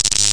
Paralyze1.ogg